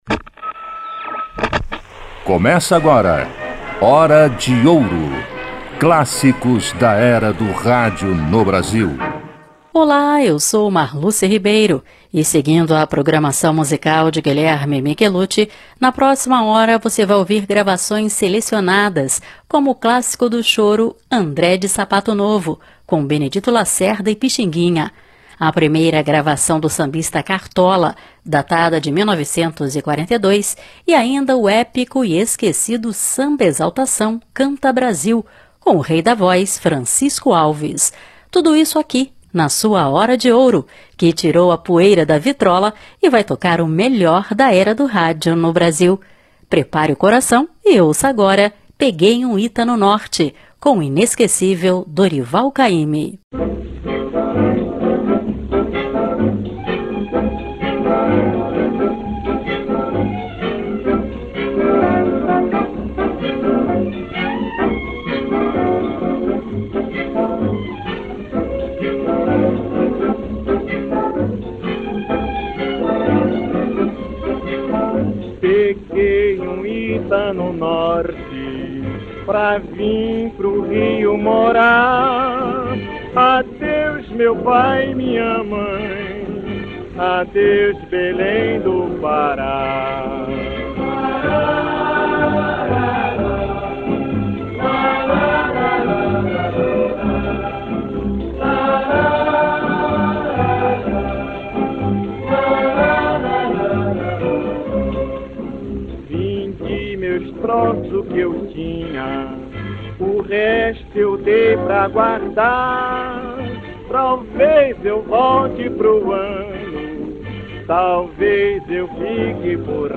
A Hora de Ouro tirou a poeira da vitrola e colocou para tocar o melhor da Era do Rádio no Brasil.
Samba